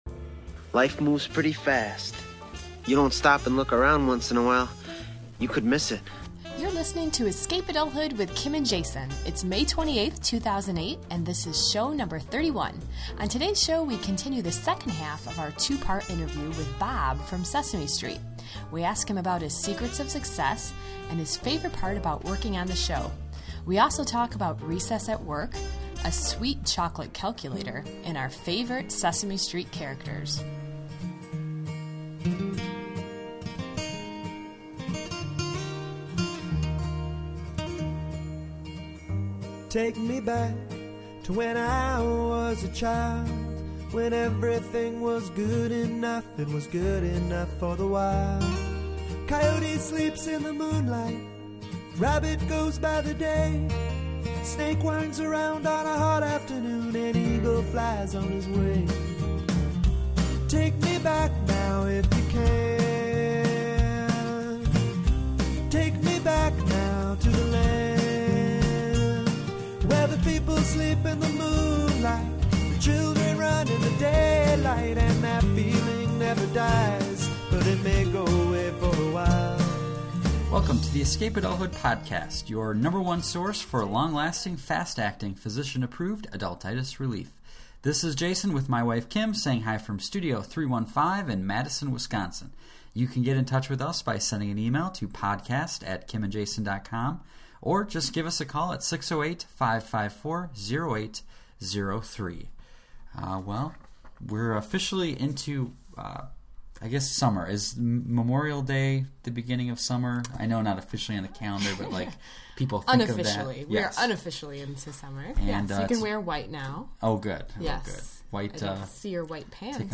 In this episode, brought to you from studio 315 in Madison, Wisconsin, we continue the second half of our two-part interview with Bob from Sesame Street. We ask him about his secrets of success and his favorite part about working on the show. We also talk about recess at work, a sweet chocolate calculator, and our favorite Sesame Street characters.